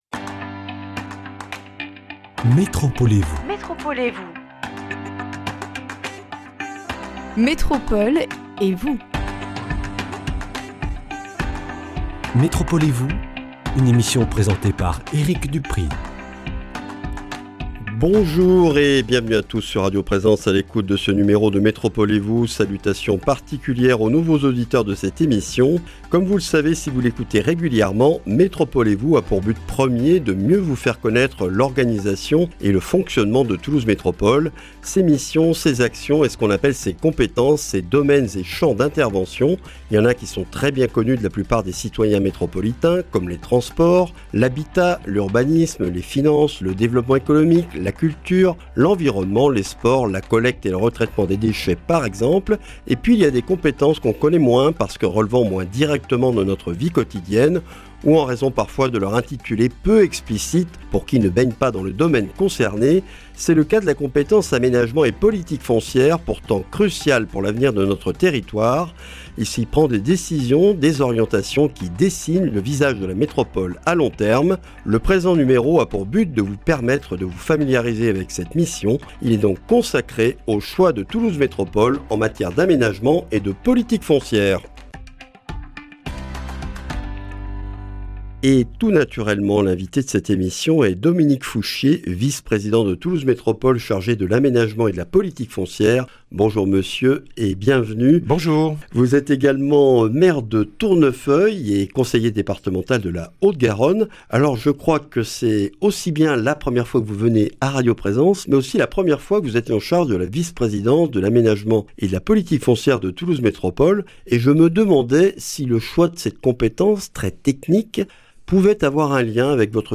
Dominique Fouchier, maire de Tournefeuille, conseiller départemental de la Haute-Garonne et vice-président de Toulouse Métropole chargé de l’Aménagement et de la Politique foncière, est l’invité de ce numéro. L’occasion de découvrir les choix et orientations de la Métropole en matière d’aménagement et de politique foncière, deux missions de 1ère importance pour l’avenir de notre territoire.